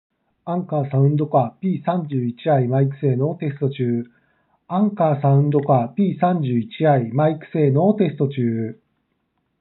マイク性能は少しこもり気味
✅「Anker Soundcore P31i」マイク性能
少しだけ聞こえいにくいマイク性能。